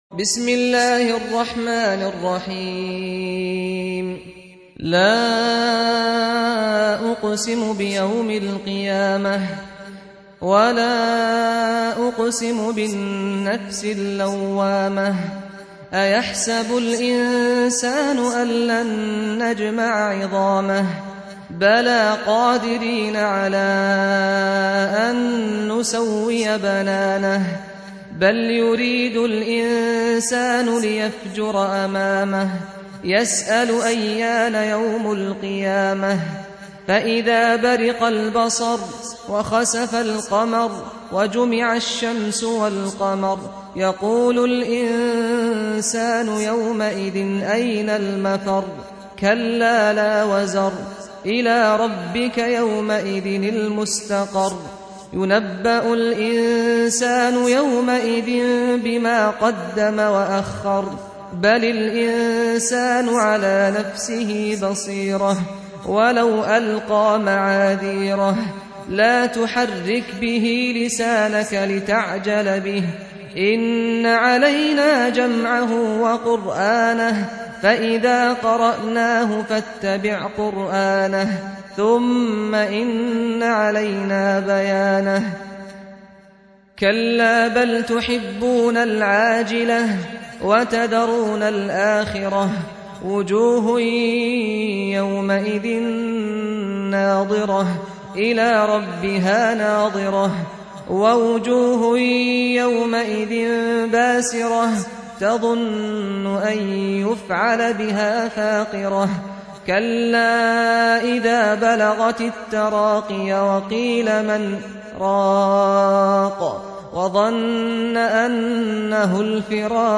Surah Al-Qiy�mah سورة القيامة Audio Quran Tarteel Recitation
حفص عن عاصم Hafs for Assem